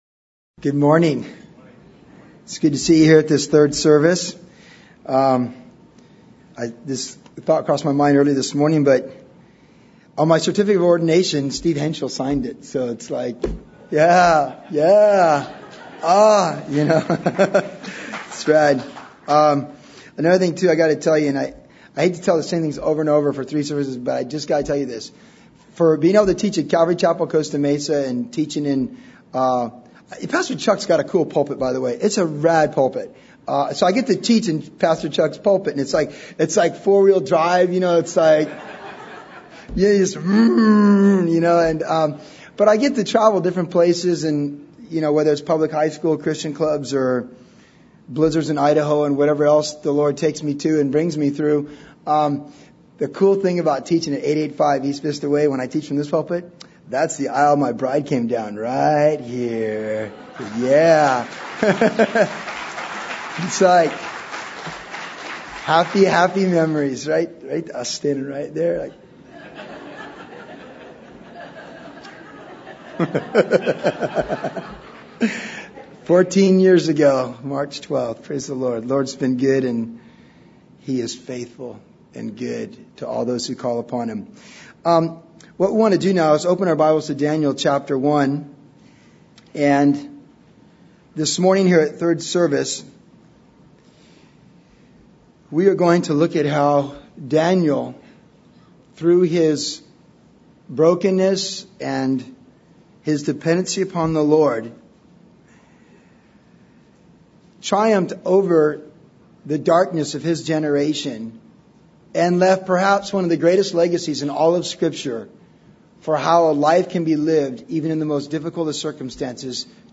MP3 Bible - Audio Sermons